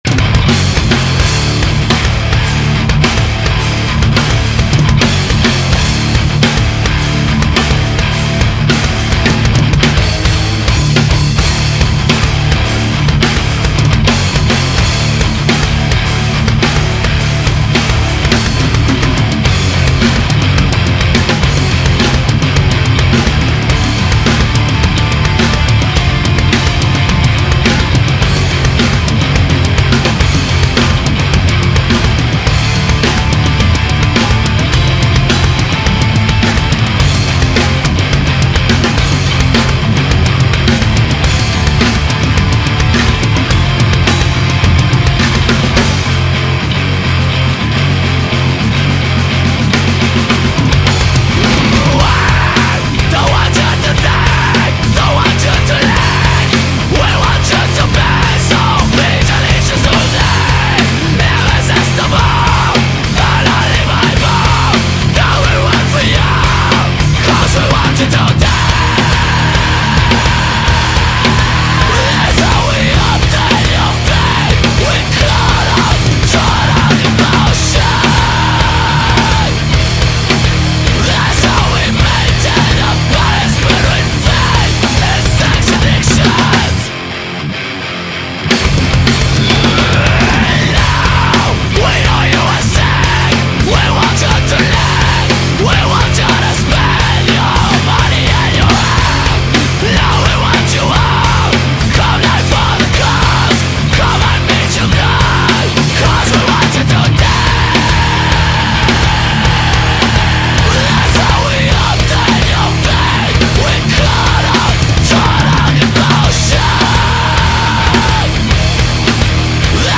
Style : Metal-Hardcore